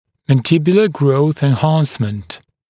[ˌmæn’dɪbjulə grəuθ ɪn’hɑːnsmənt] [en-][ˌмэн’дибйулэ гроус ин’ха:нсмэнт] [эн-]увеличение роста нижней челюсти